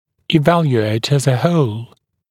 [ɪ’væljueɪt əz ə həul][и’вэлйуэйт эз э хоул]оценивать в целом